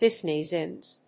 発音記号
• / bízinəs(米国英語)